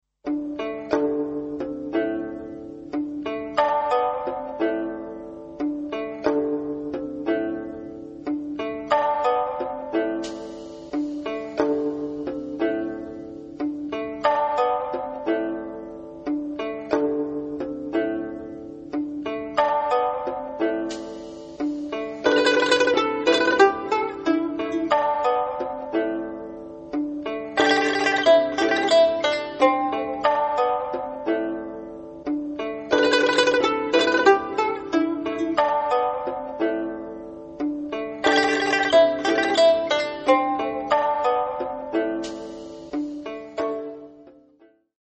Guitar
2005 relaxed slow instr.